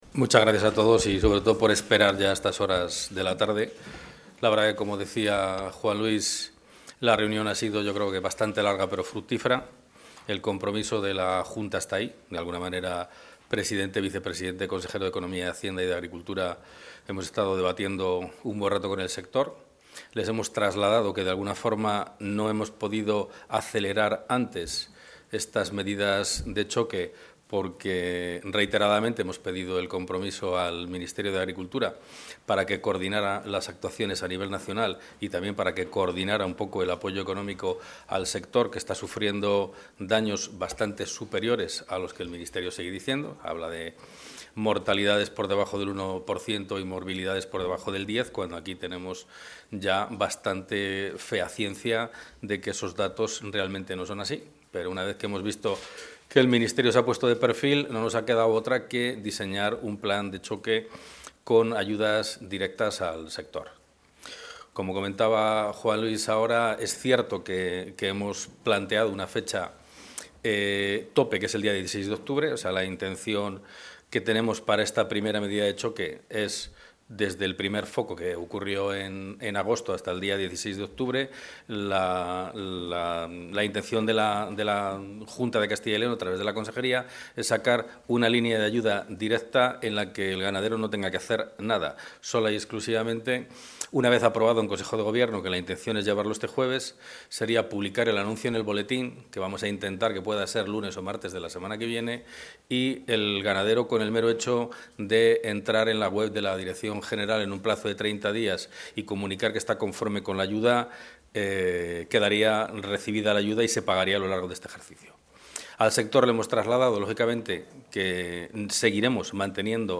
Intervención del consejero de Agricultura, Gandería y Desarrollo Rural.